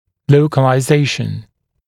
[ˌləukəlaɪ’zeɪʃ(ə)n][ˌлоукэлай’зэйш(э)н]локализация, определение местонахождения